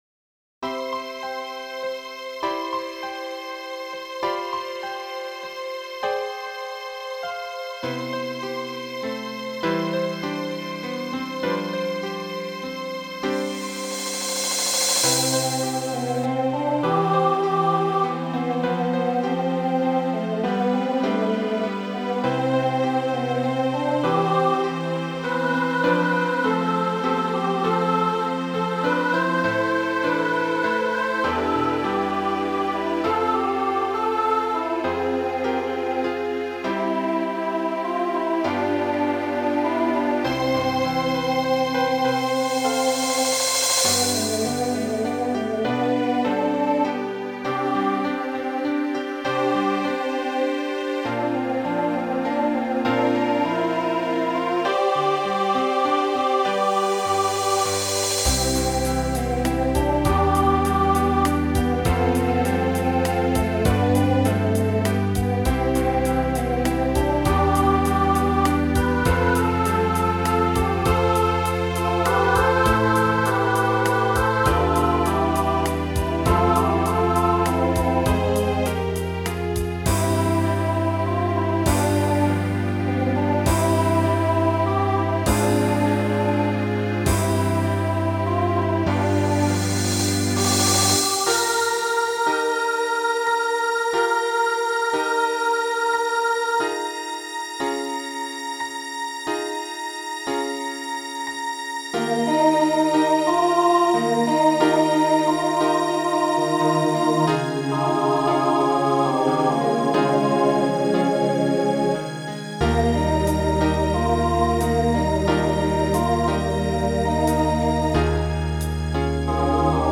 Voicing SATB Instrumental combo Genre Broadway/Film
2010s Show Function Ballad